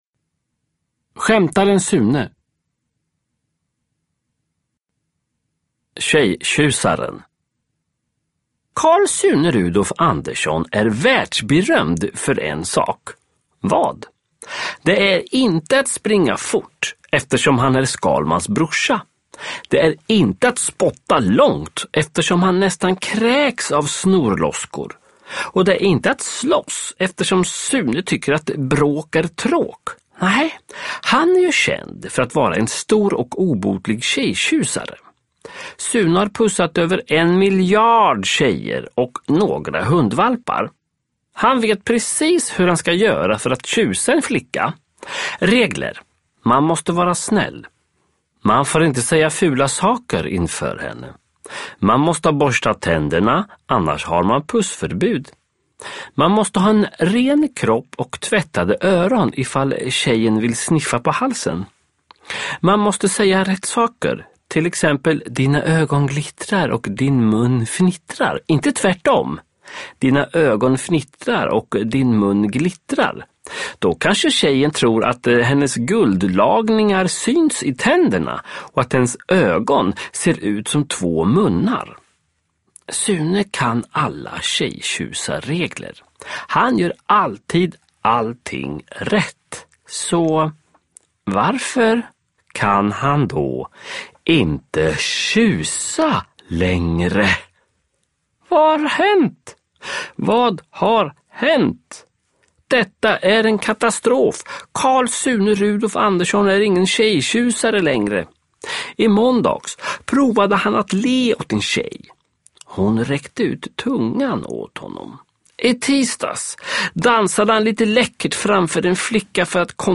Skämtaren Sune (ljudbok) av Sören Olsson